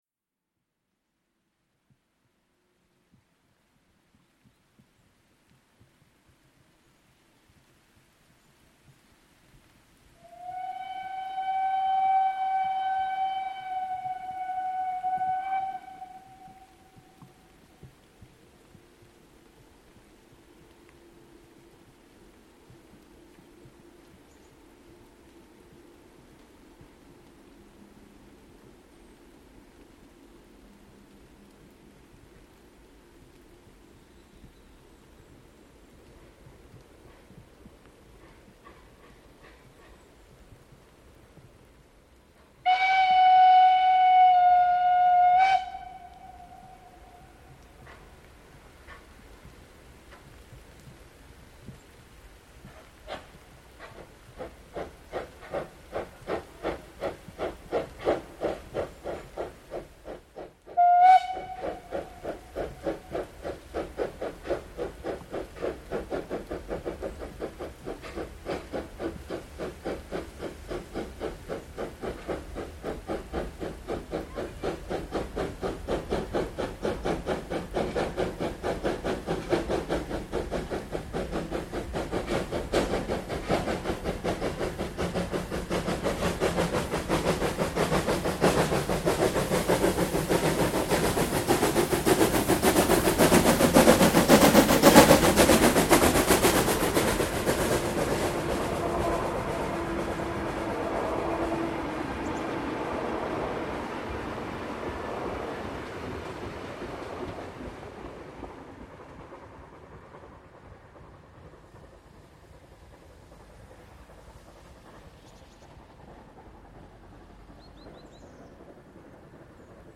Nicht viel leiser als die Aufnahme am Vormittag an dieser Stelle, kurze aber schön laute „Bergfahrt“:
98 886  mit Zug Mellrichstadt→Fladungen an der schon wiederholt aufgesuchten Aufnahmestelle: wieder hinter Ausfahrt Mellrichstadt im Einschnitt bei Straße „An der Lehmgrube“, immer noch oder besser: wieder mal im Regen, um 16:12h am 03.08.2025.   Hier anhören: